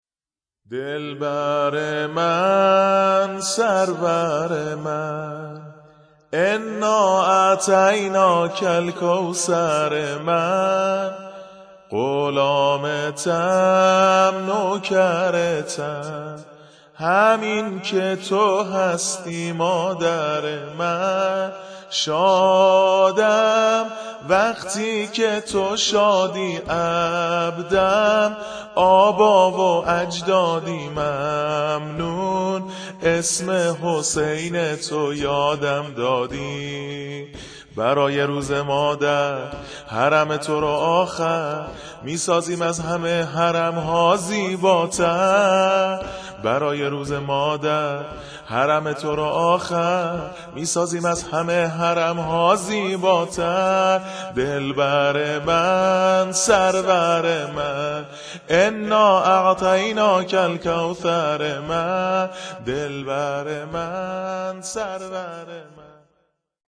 شور ، سرود